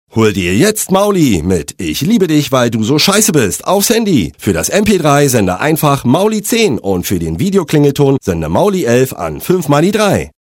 Sprecher und Stimmen
Imagefilm Charite